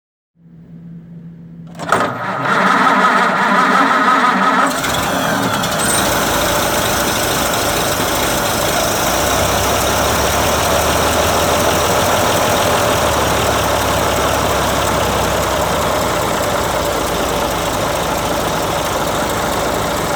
1917-Haynes-motor.mp3